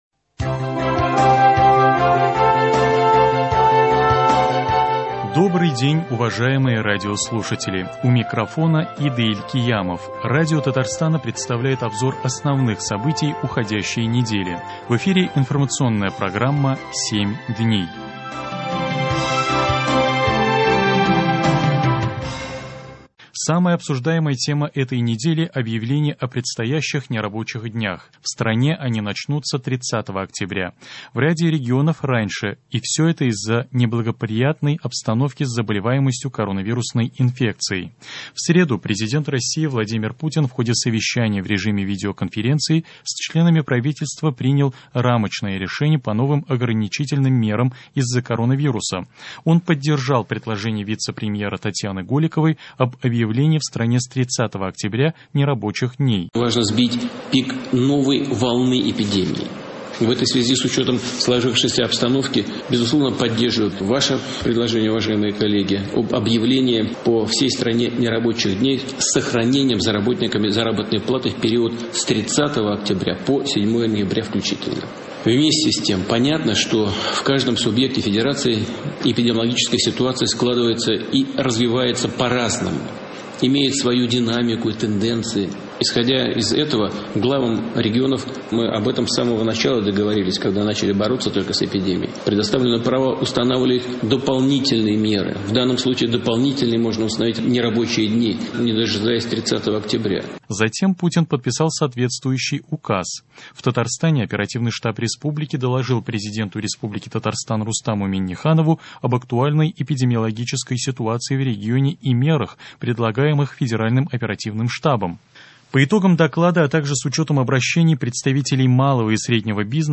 Обзор событий.